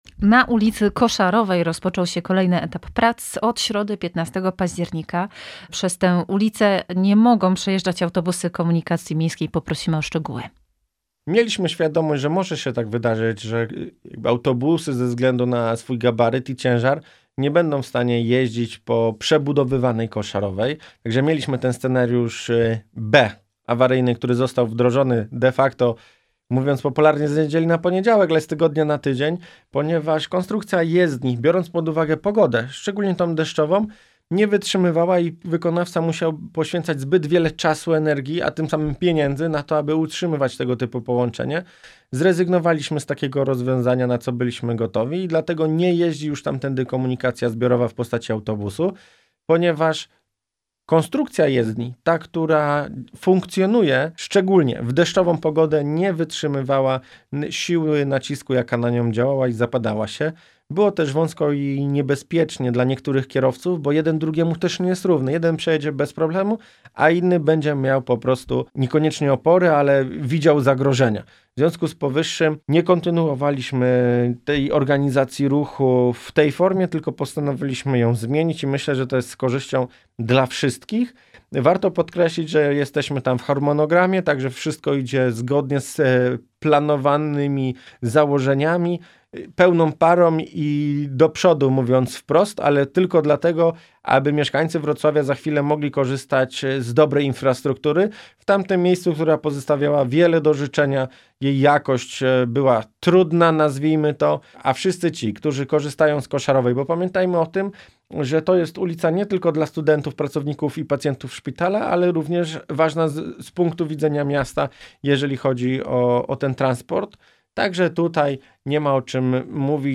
Więcej o tym w rozmowie